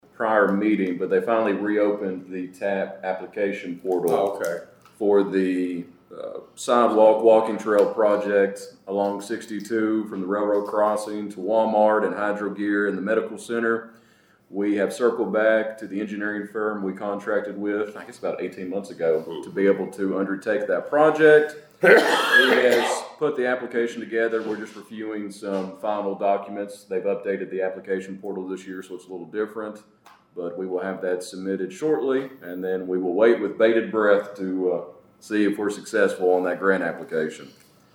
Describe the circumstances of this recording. Caldwell County Fiscal Court heard updates on proposed and ongoing community projects during a brief meeting on Tuesday morning.